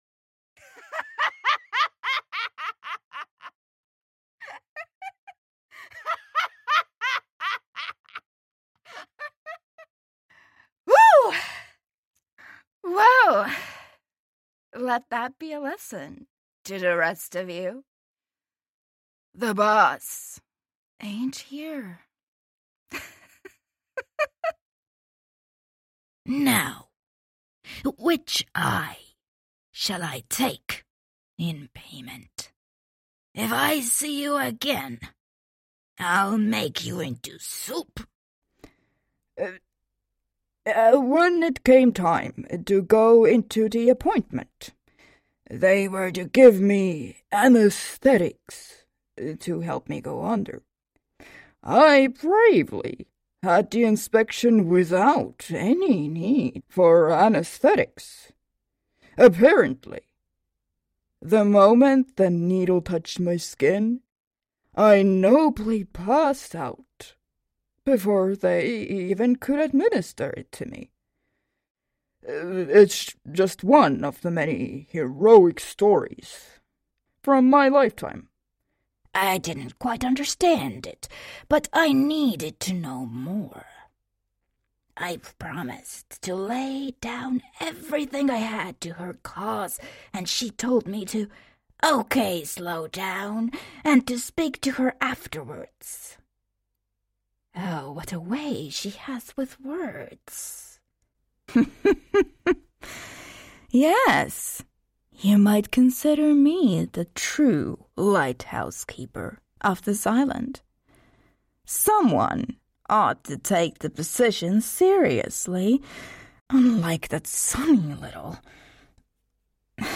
Videojuegos
Soy locutora estonia nativa y trabajo tanto en estonio como en inglés, ¡con un ligero acento!
Como hablante nativo de estonio, normalmente tengo un ligero acento.
Identidades tanto masculinas como femeninas.